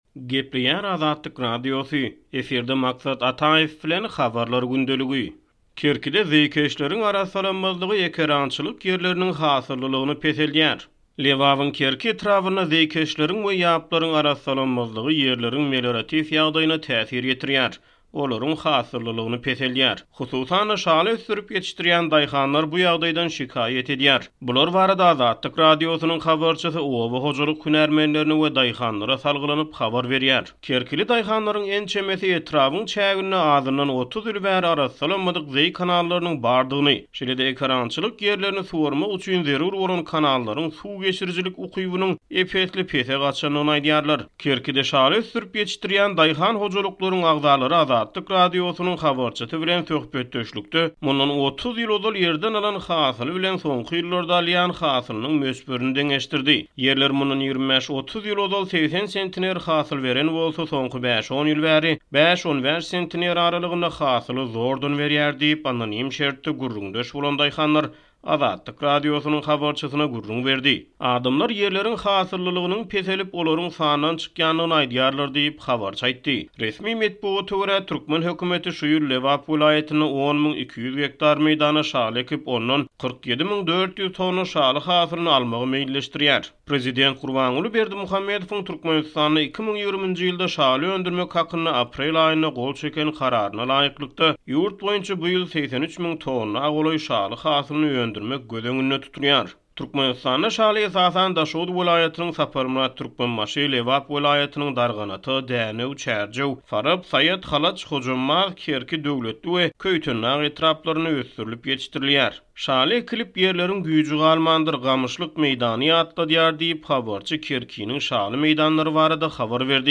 Bular barada Azatlyk Radiosynyň habarçysy oba hojalyk hünärmenlerine we daýhanlara salgylanyp habar berýär.